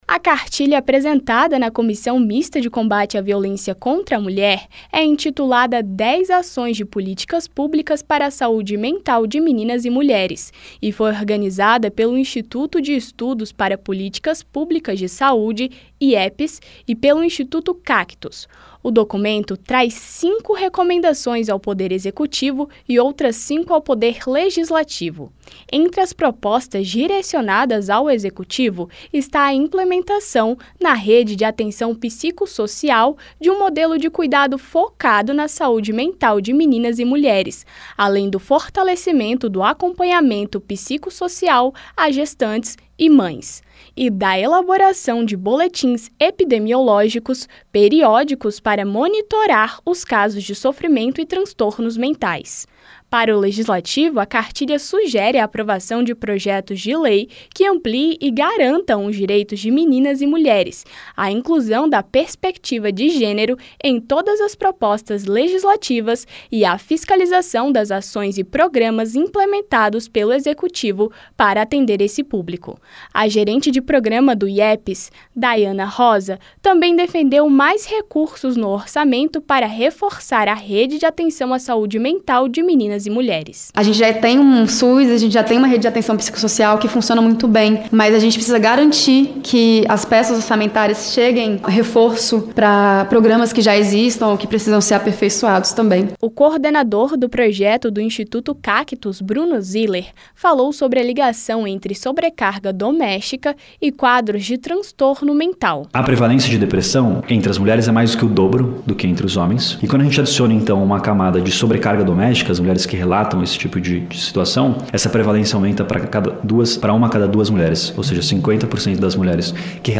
Audiência Pública